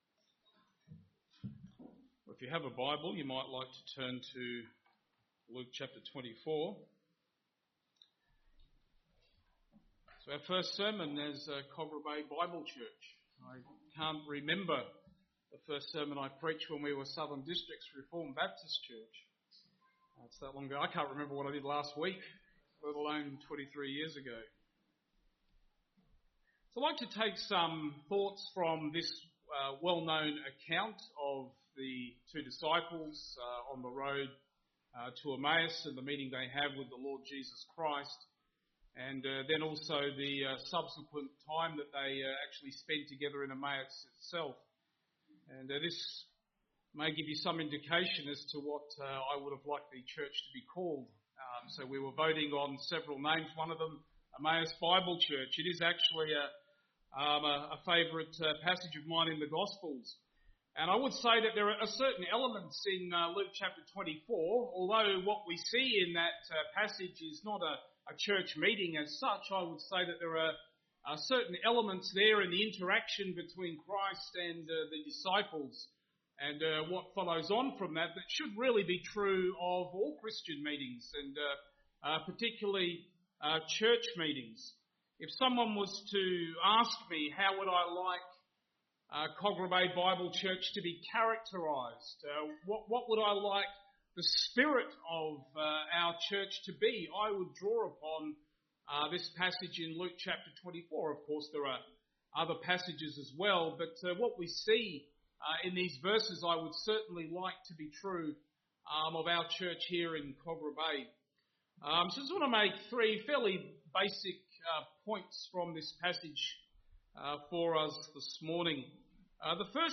constitution_service_sermon.mp3